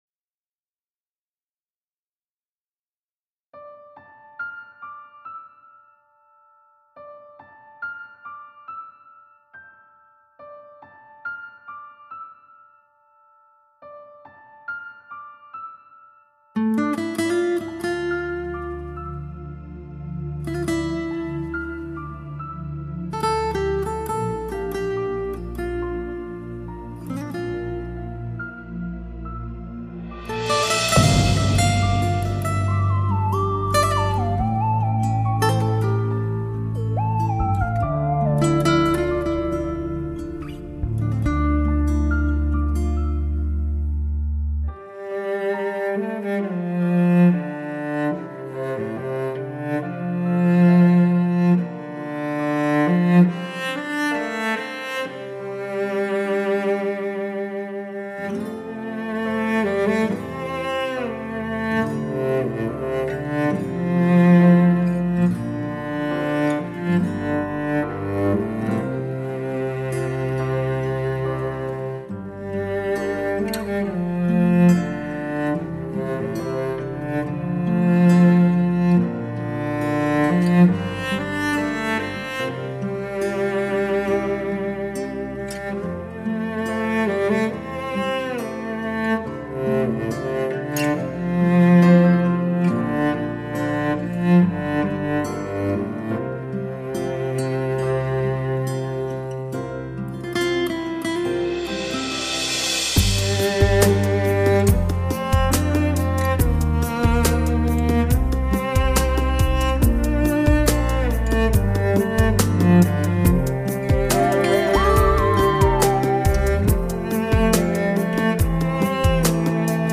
音符自由流淌，或典雅明丽，或华丽多彩，或遥远震颤，或凝重悲怆。
闭目聆听，婉转缠绵的二胡，悠扬空旷的马头琴，醇厚幽远的古筝，
轻扬灵性的笛子，清旷古远的箫……巧妙地融合在一起，如丝般地划过心头，